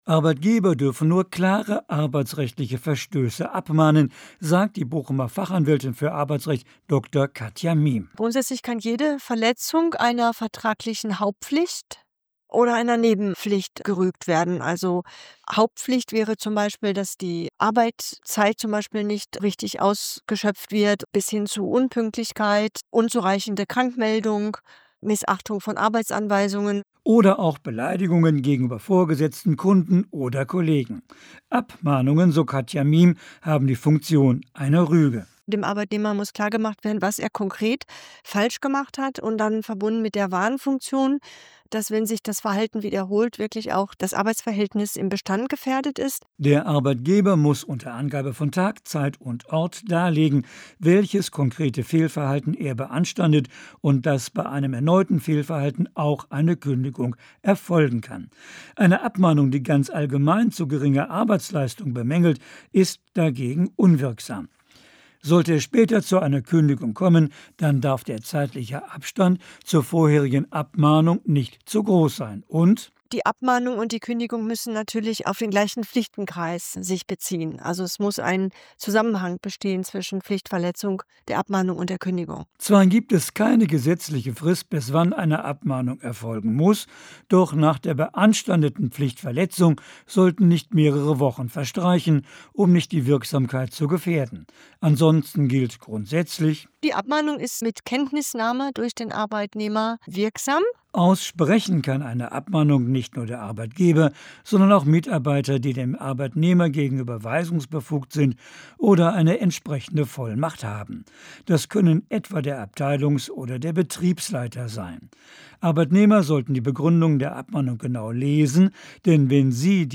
Im Folgenden finden Sie einige ausgewählte Radiointerviews: